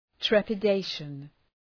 Προφορά
{,trepə’deıʃən}